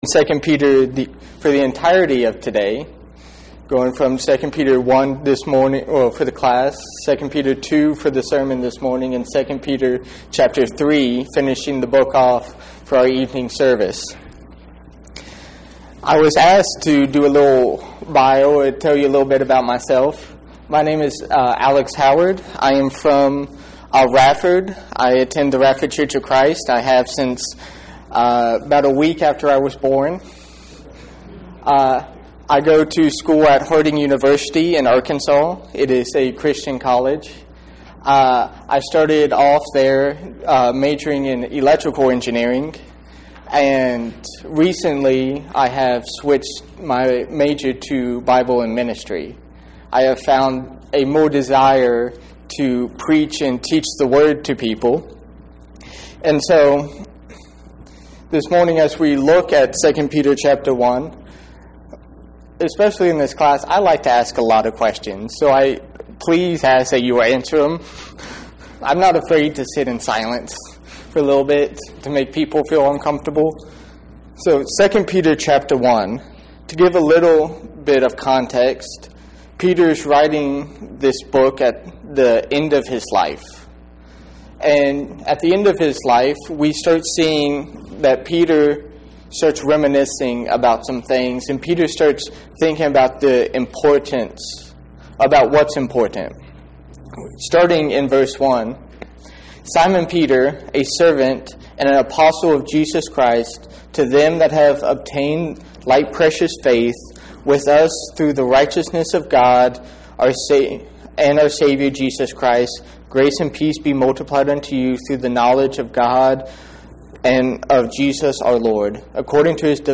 Bible Study – II Peter Chapter 1